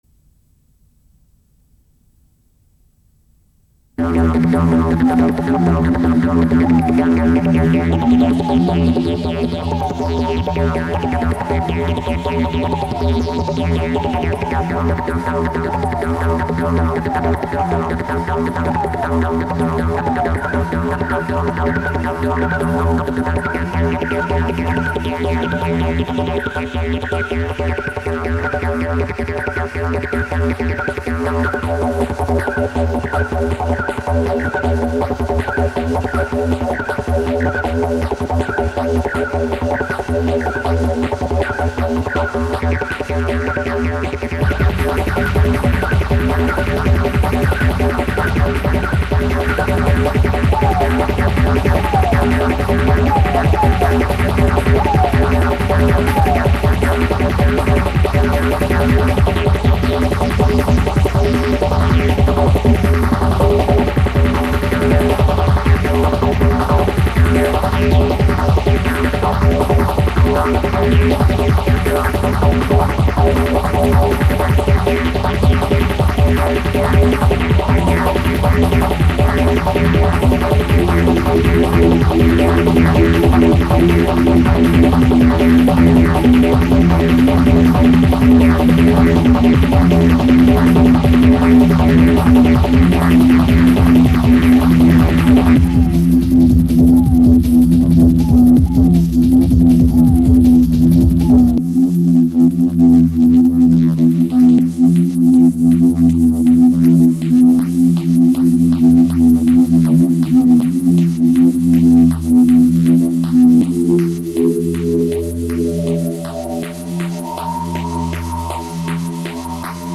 location Cologne, Germany event Eis-und Schwimmstadion